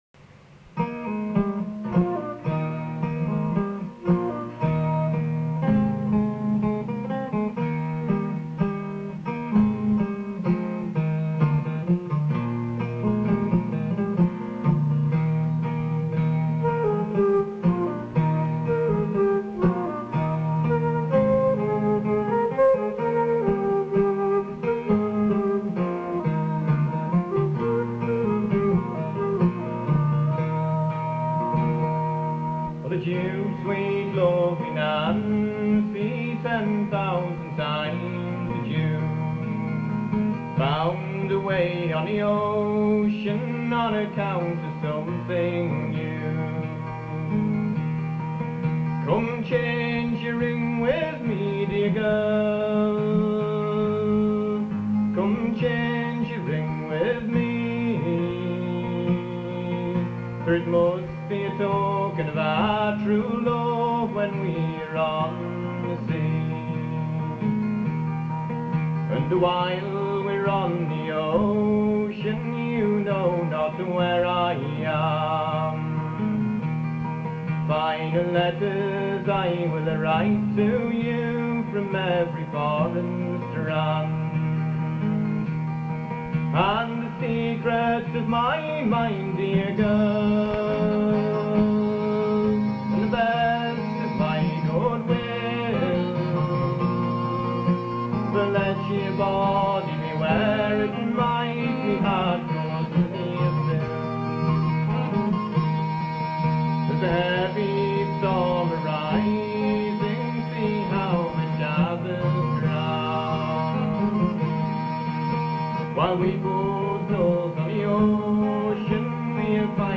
The recording quality was probably never very good and has inevitably deteriorated over the years but still gives an idea of the nature of the material and the approach to its performance.
Chanter and Flute
Lead Vocals and Guitar